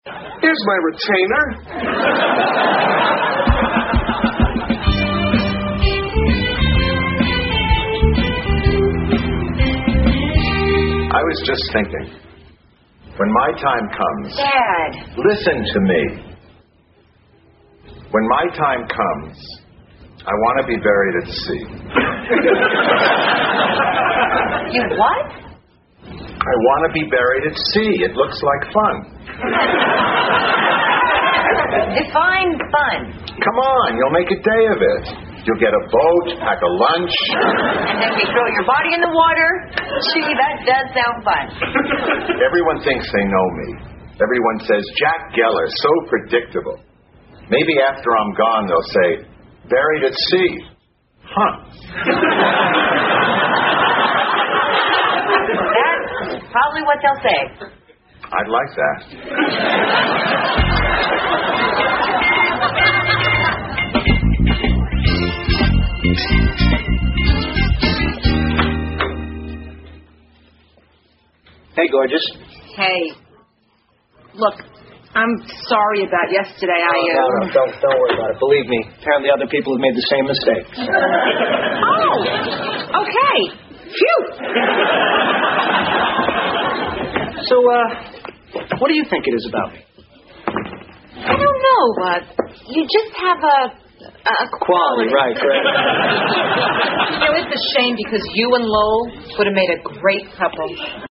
在线英语听力室老友记精校版第1季 第92期:祖母死了两回(6)的听力文件下载, 《老友记精校版》是美国乃至全世界最受欢迎的情景喜剧，一共拍摄了10季，以其幽默的对白和与现实生活的贴近吸引了无数的观众，精校版栏目搭配高音质音频与同步双语字幕，是练习提升英语听力水平，积累英语知识的好帮手。